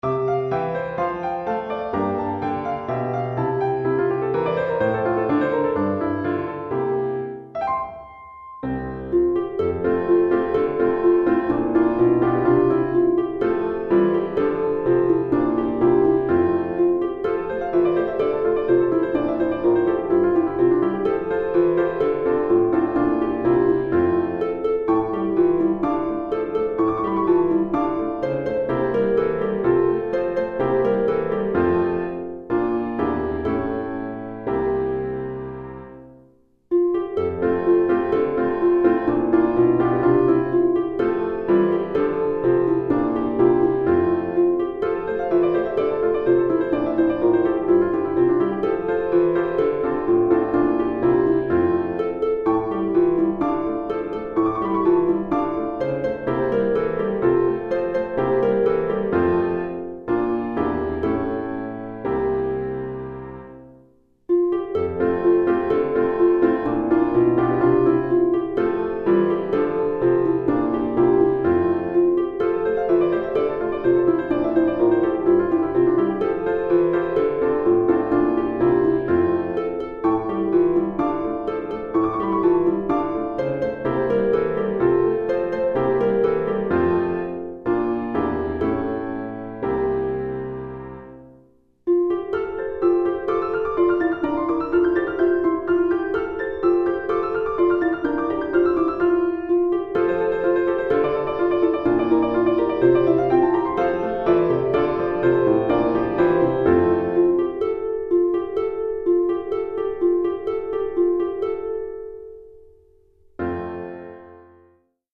Children's Choeur 1 Piano